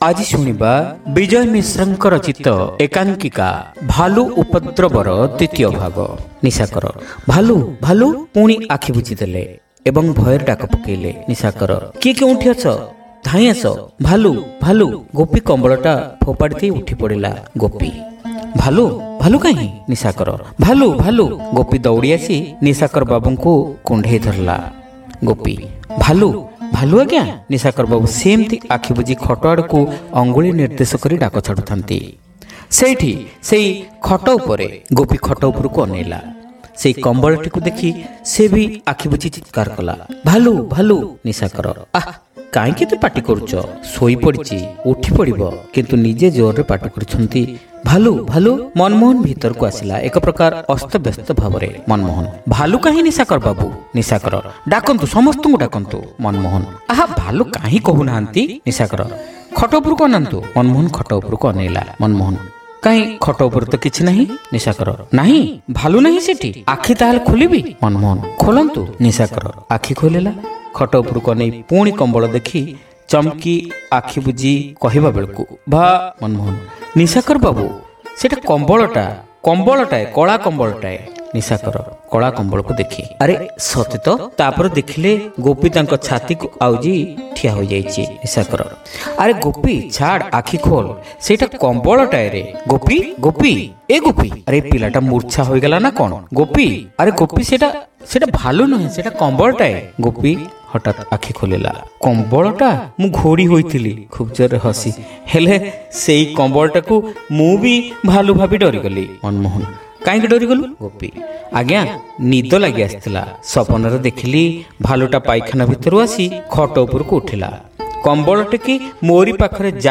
Audio One act Play : Bhalu Upadraba (Part-2)